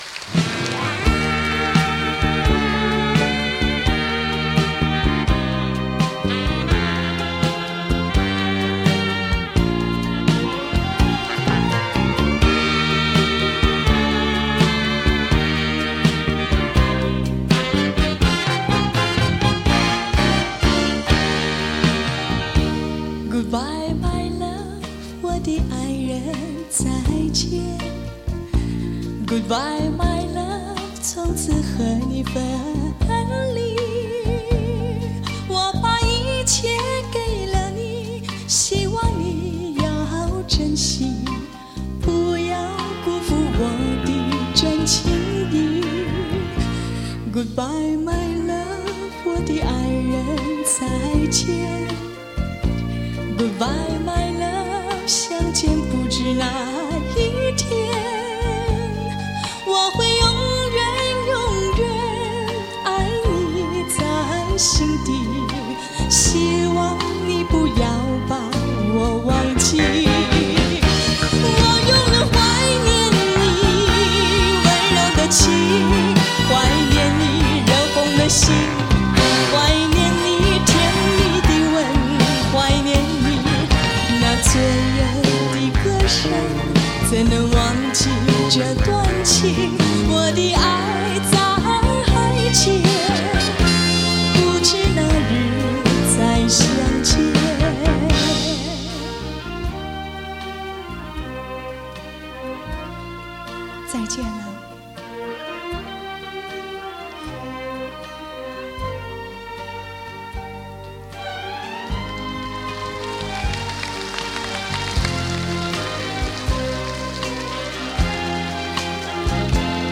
足本3CD 斥资重构原声母带 2012全新混音
时光倒流 呈现1982年伊馆演唱会原神真髓声效
完整实况 足本曝光 一气呵成